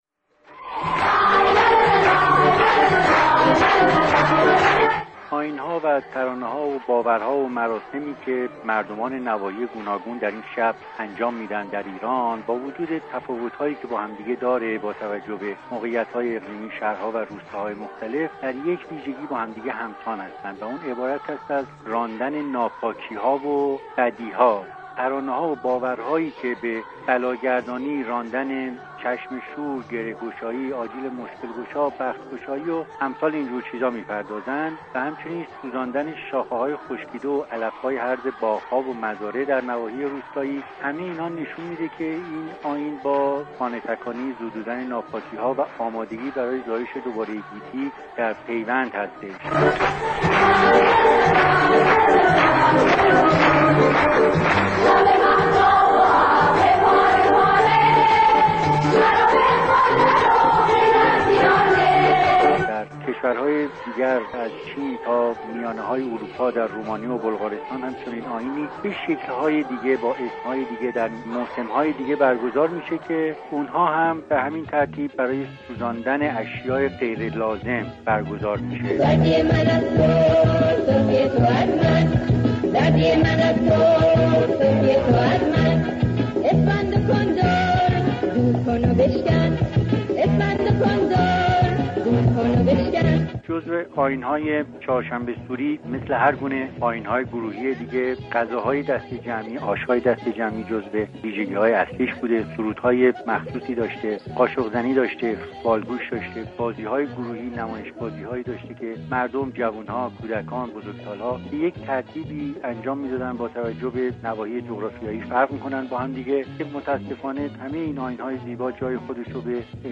بازپخش گفتگوی